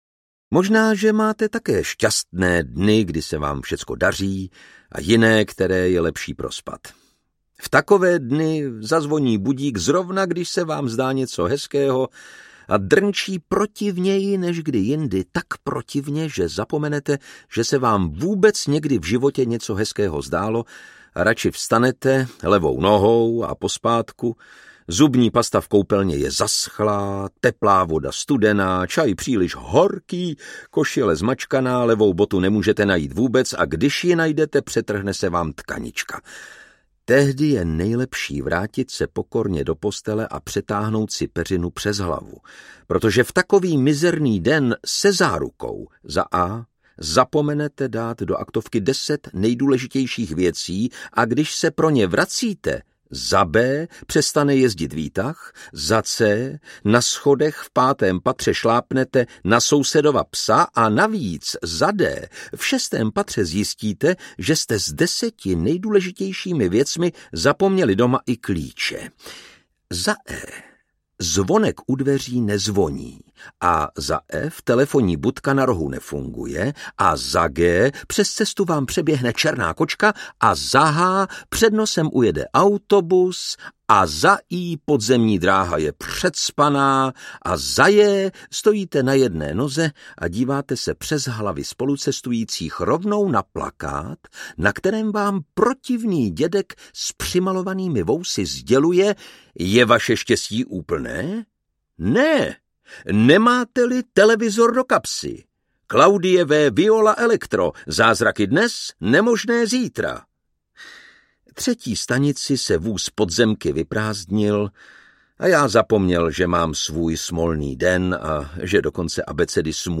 Pan Tau a tisíc zázraků audiokniha
Ukázka z knihy
• InterpretLukáš Hlavica
pan-tau-a-tisic-zazraku-audiokniha